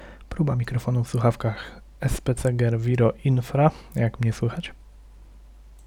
Głos zbierany przez niego jest wyraźny i dość czysty, ponad to nie zbiera przesadnie dźwięków tła. Poniżej próbka głosu nim nagrana.